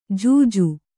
♪ jūju